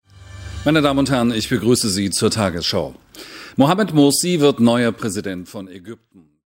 Jens Riewa am 25.06.2012 um 1 Uhr 14 begrüßt zur "tagesshow"